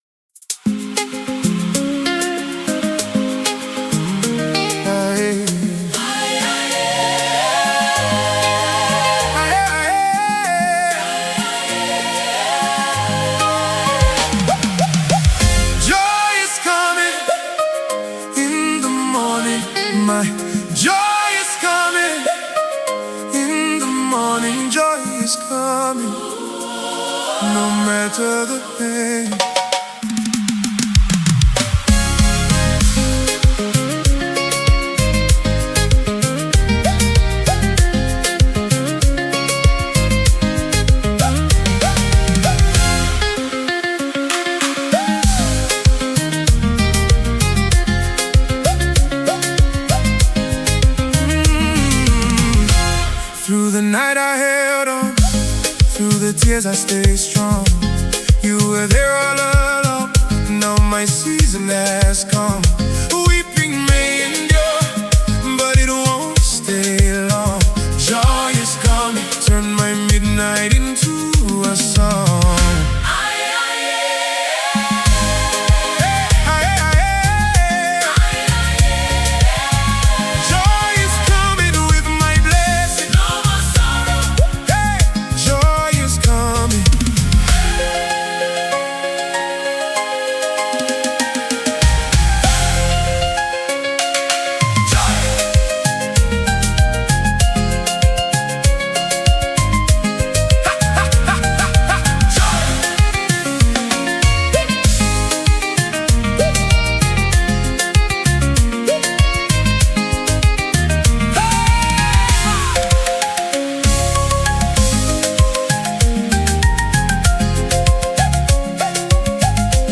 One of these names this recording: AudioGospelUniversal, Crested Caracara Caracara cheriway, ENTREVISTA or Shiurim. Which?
AudioGospelUniversal